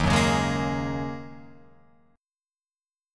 D#m11 chord